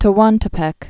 (tə-wäntə-pĕk, tĕ-wäntĕ-), Isthmus of